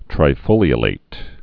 (trī-fōlē-ə-lāt)